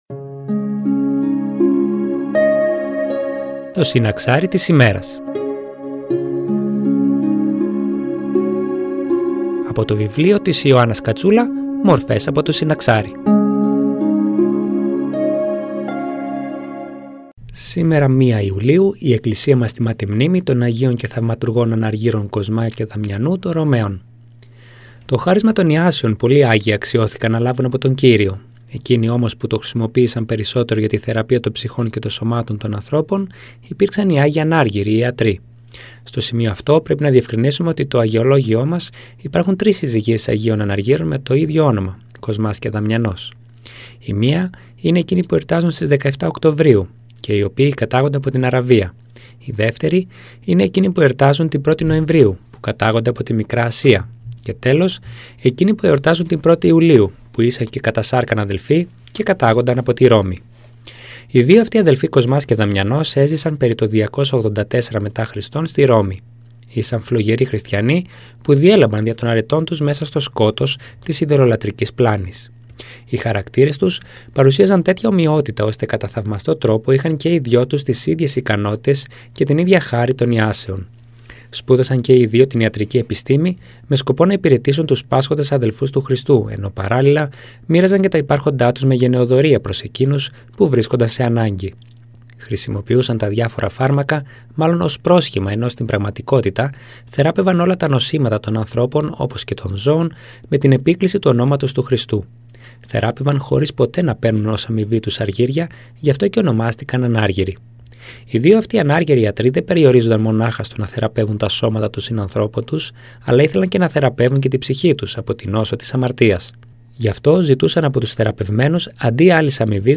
Εκκλησιαστική εκπομπή